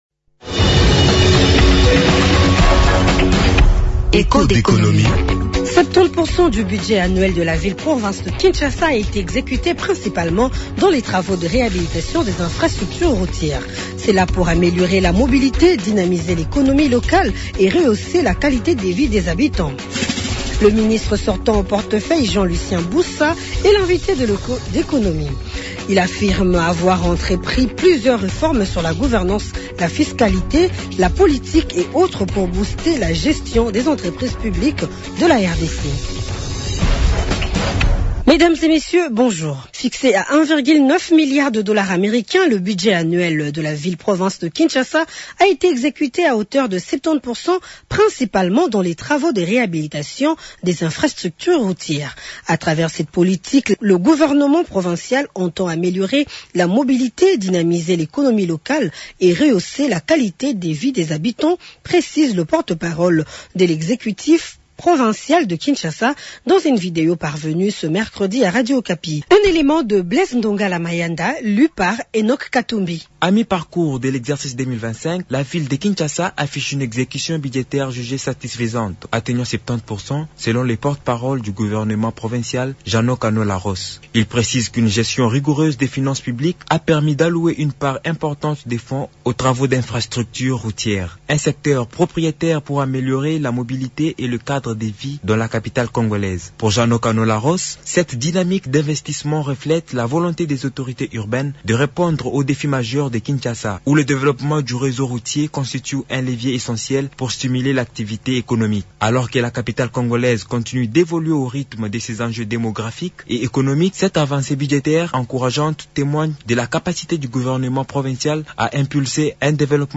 - Invité Jean-Lucien Busa, ministre sortant au Portefeuille.